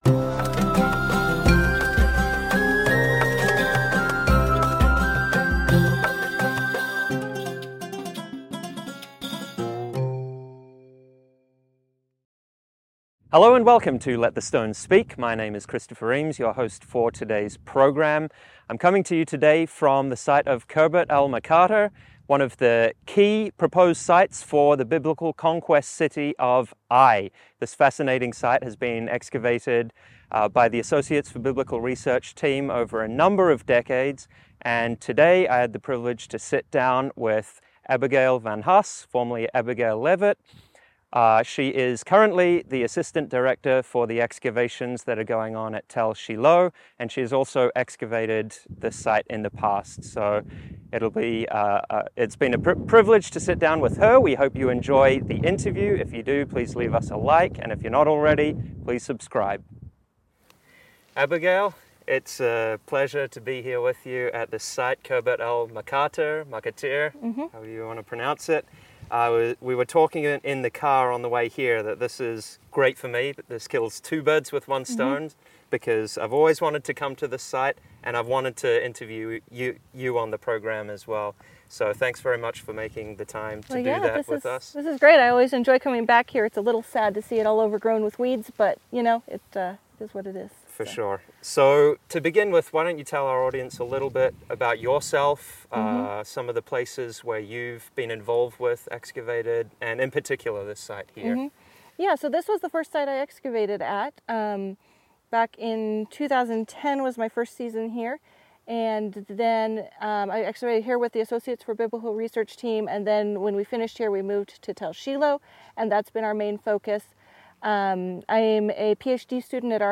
This small site conforms with a number of key parameters for Joshua’s Ai. In today’s interview, which was recorded in May